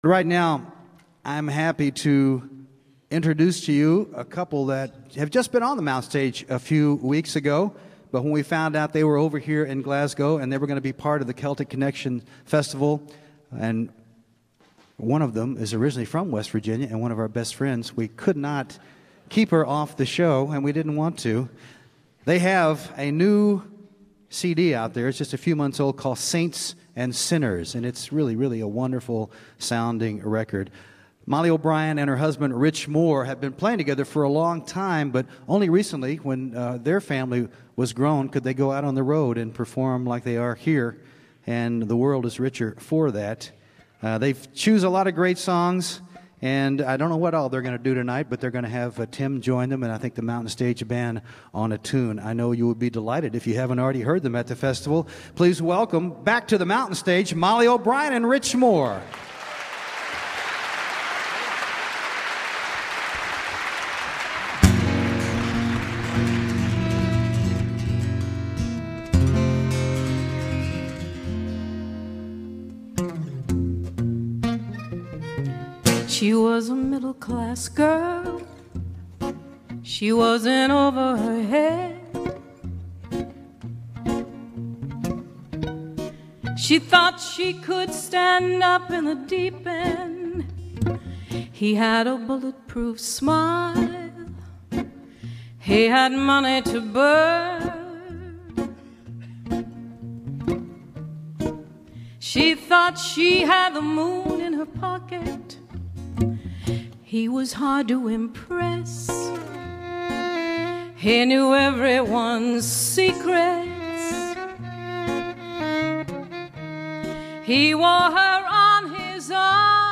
at the 2011 Celtic Connection Festival in Glasgow, Scotland.